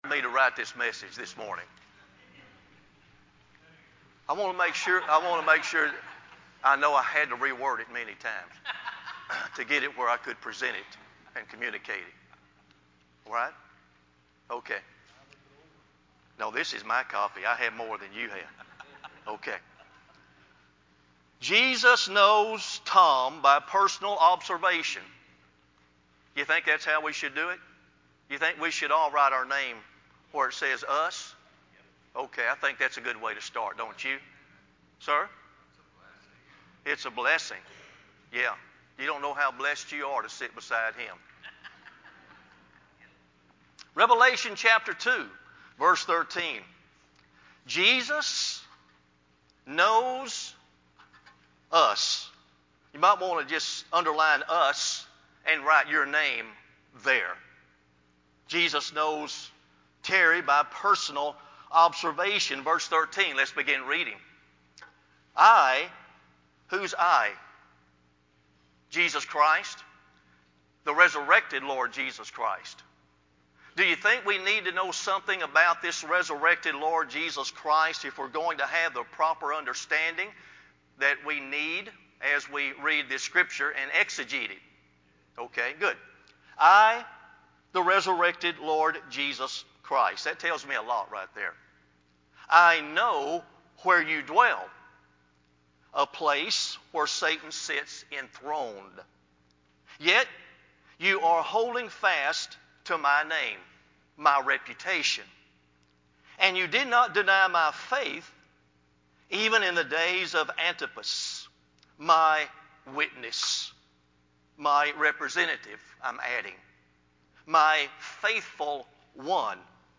sermon-11-29-CD.mp3